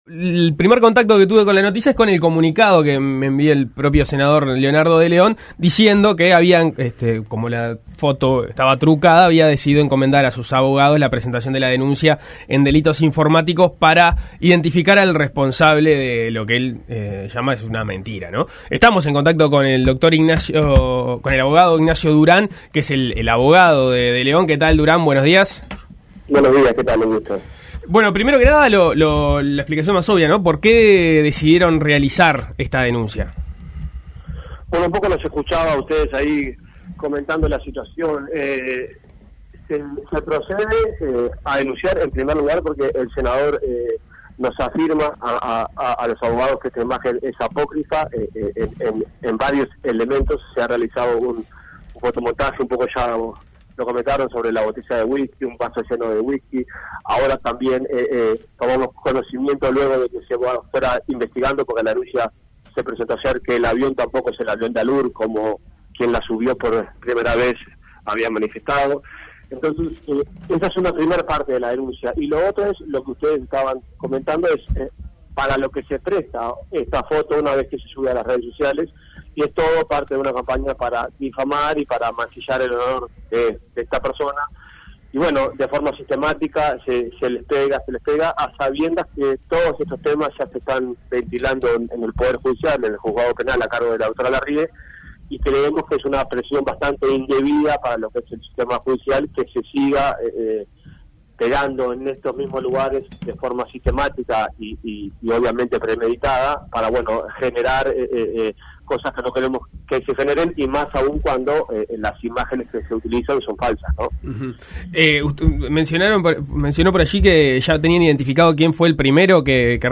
Entrevistado por Suena Tremendo